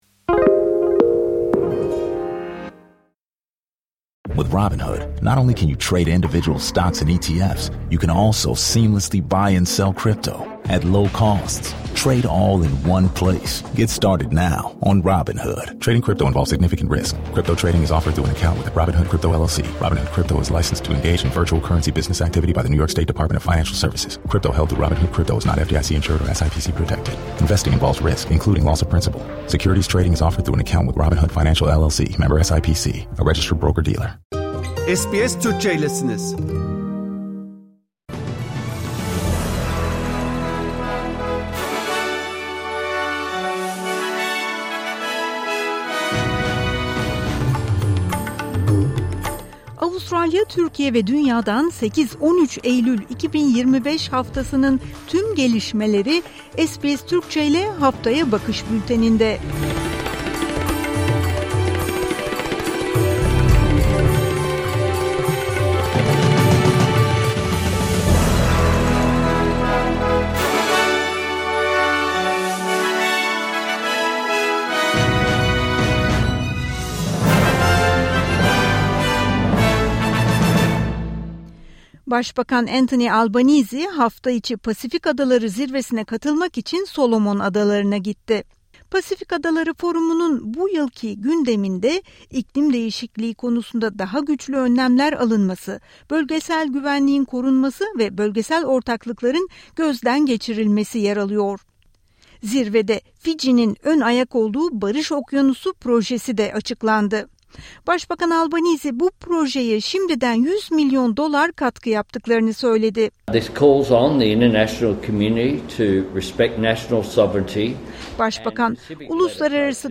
Avustralya, Türkiye ve dünyadan 8 –13 Eylül 2025 haftasının tüm gelişmeleri SBS Türkçe ile Haftaya Bakış bülteninde.